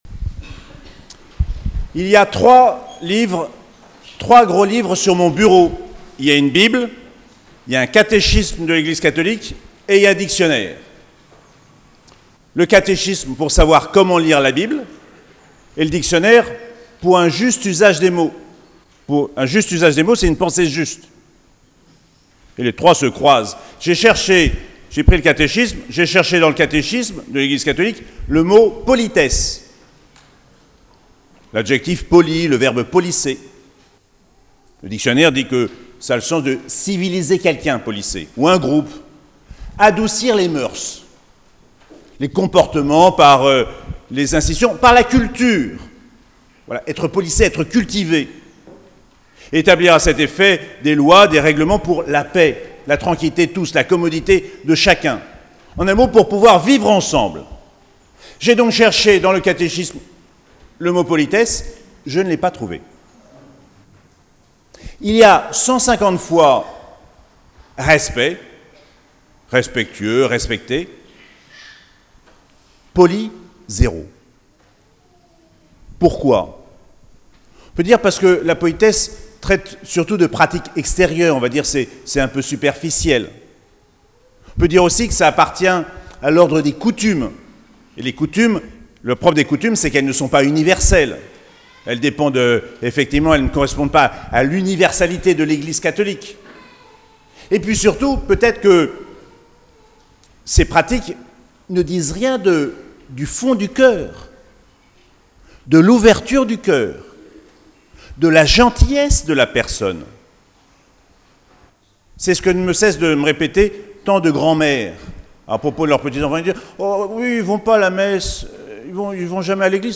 28ème dimanche du temps ordinaire - 9 octobre 2022